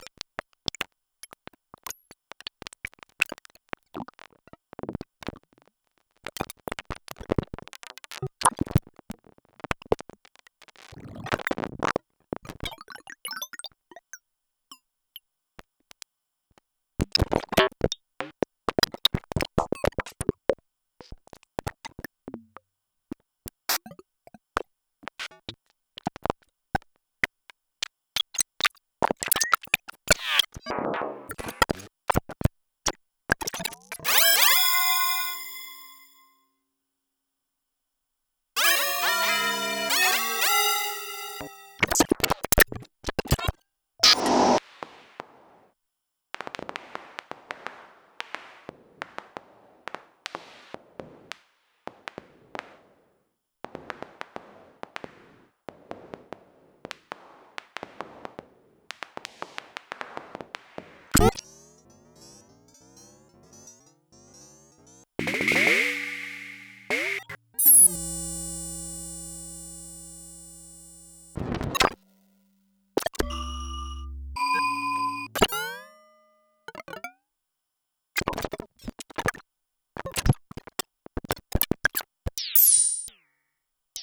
Micro_Percussion
mgreel micro-percussion morphagene percussion plonk sound effect free sound royalty free Memes